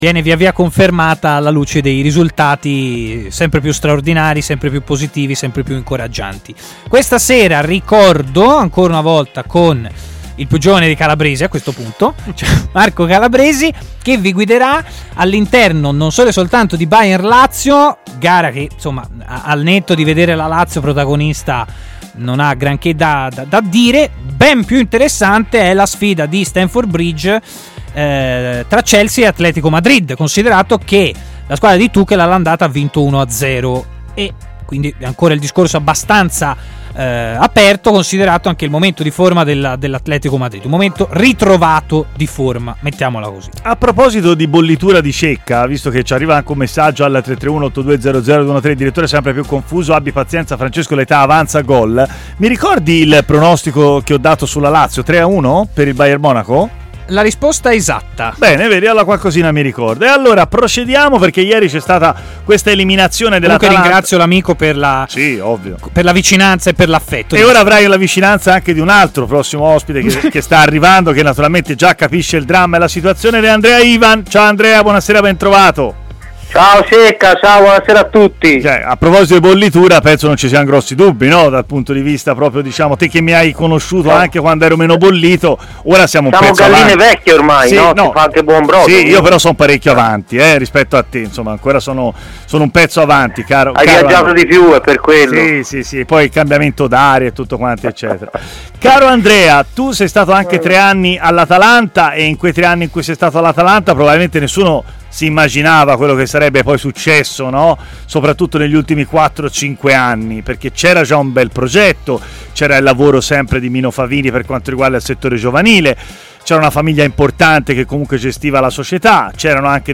ha parlato in diretta ai microfoni di TMW Radio, nel corso della trasmissione Stadio Aperto